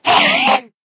scream3.ogg